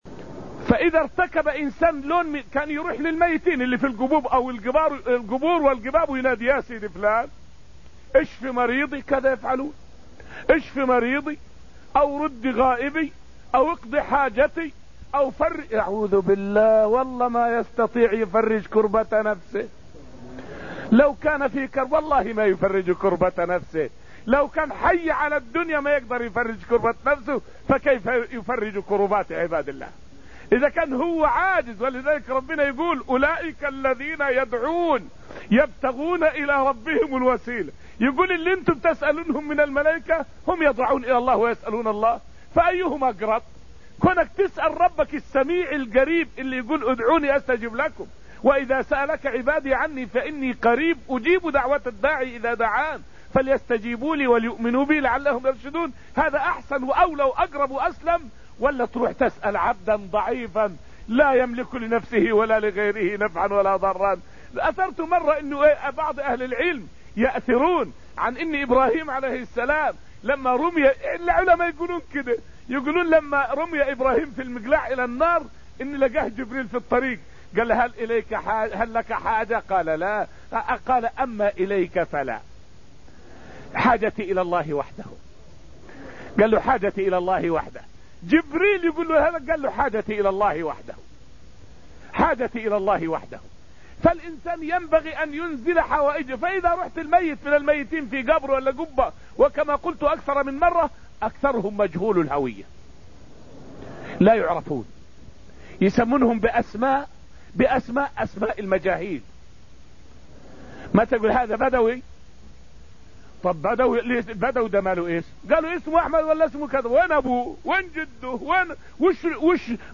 فائدة من الدرس الثامن من دروس تفسير سورة الحديد والتي ألقيت في المسجد النبوي الشريف حول معنى قوله تعالى: {ادعونِي أستجب لكم}.